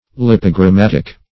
Search Result for " lipogrammatic" : The Collaborative International Dictionary of English v.0.48: Lipogrammatic \Lip`o*gram*mat"ic\ (l[i^]p`[-o]*gr[a^]m*m[a^]t"[i^]k), a. [Gr. lipogra`mmatos: cf. F. lipogrammatique.]
lipogrammatic.mp3